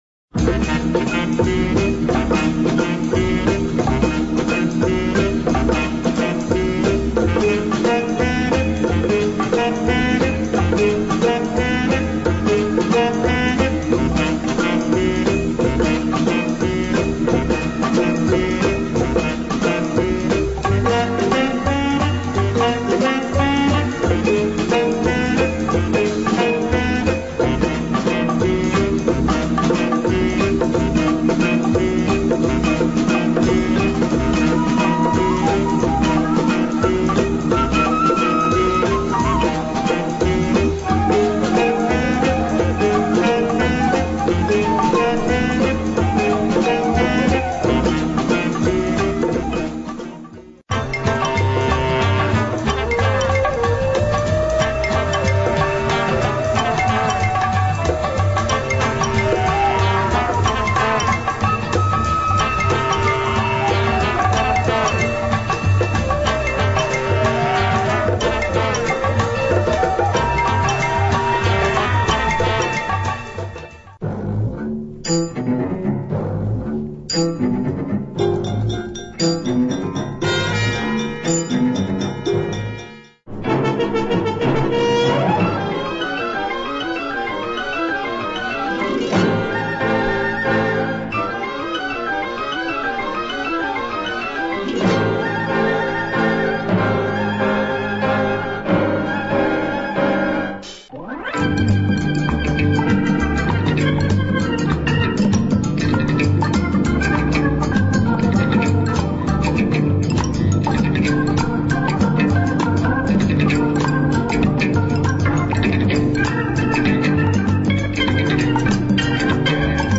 British crime jazz with nice organ beat and flute